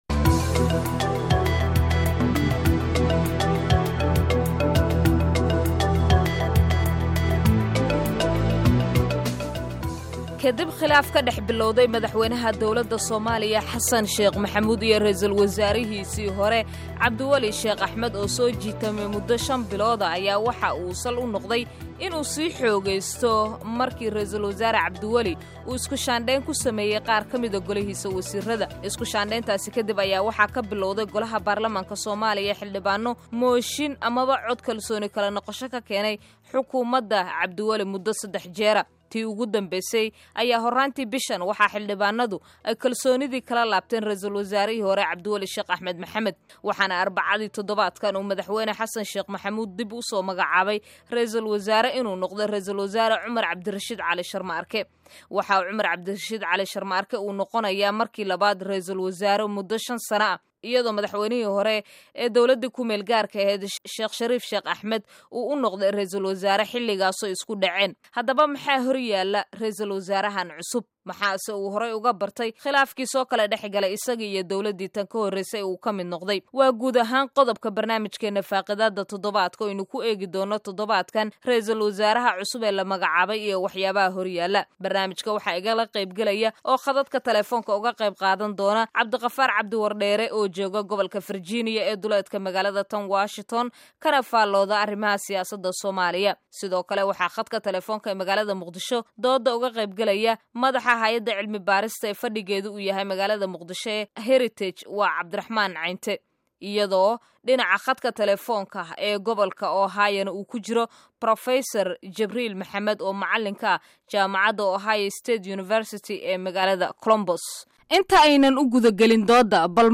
Dhagayso: Dood iyo Falanqayn ku soobsan Raysul Wasaare Sharmarke
Idaacada afka soomaaliga ku baxda ee VOA ayaa maanta barnaamijkeedii Faaqidaadda ku soo qaadatay Dood iyo Falanqayn ku soobsan Raysul Wasaare Sharmarke.